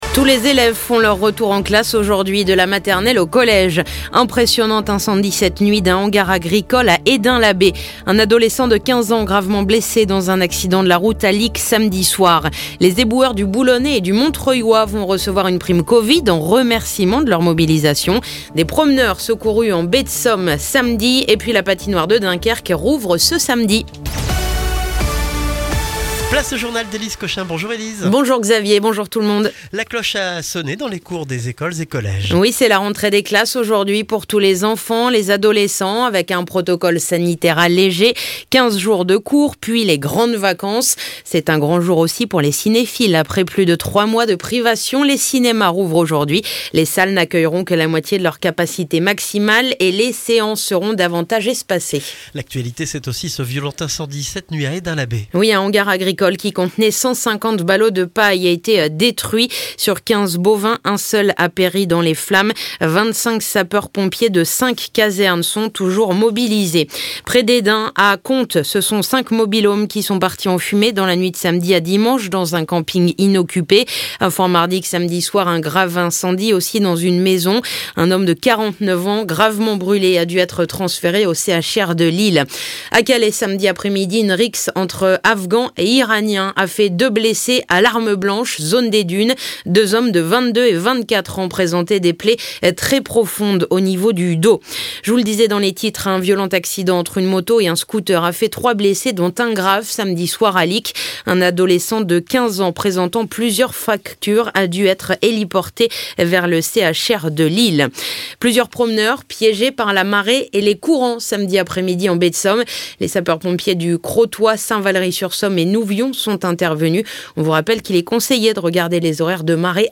Le journal du lundi 22 juin